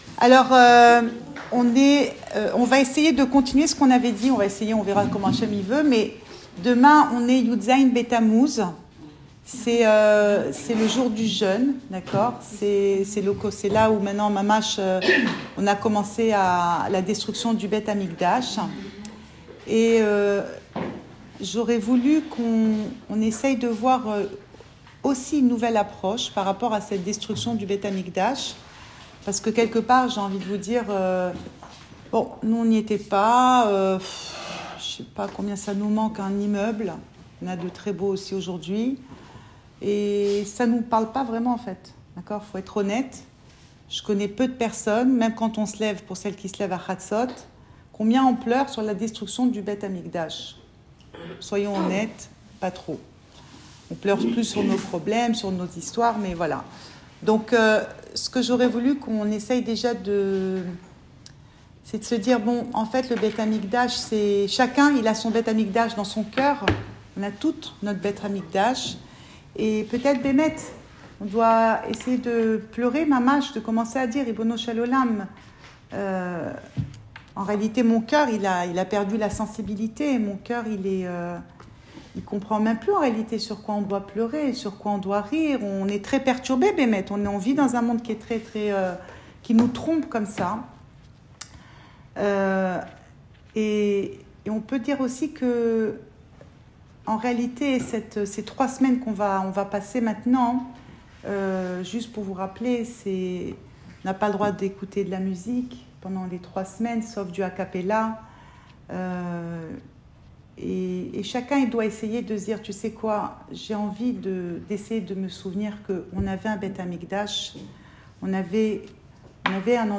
Cours audio Le coin des femmes Pensée Breslev - 8 juillet 2020 8 juillet 2020 Le 17 Tamouz. Enregistré à Tel Aviv